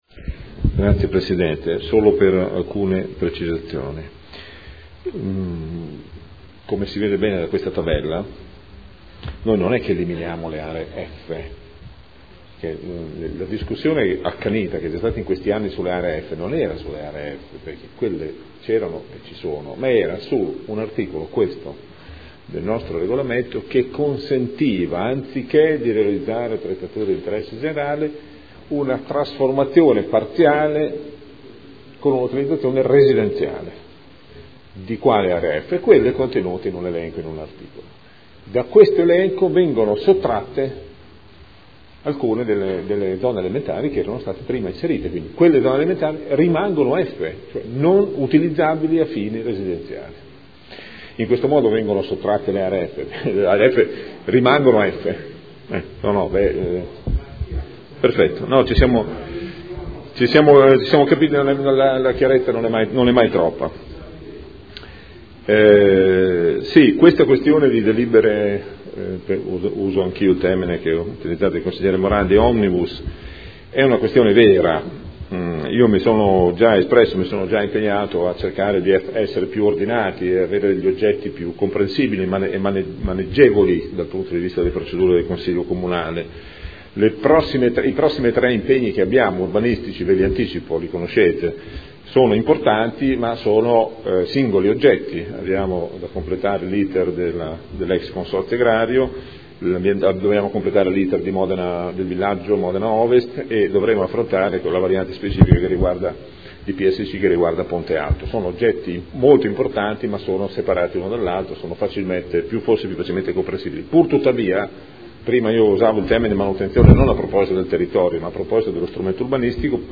Gabriele Giacobazzi — Sito Audio Consiglio Comunale
Seduta del 19 dicembre. Proposta di deliberazione: Variante al Piano Operativo Comunale (POC) e al Regolamento Urbanistico Edilizio (RUE) – Adozione.